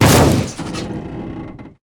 car-metal-impact-6.ogg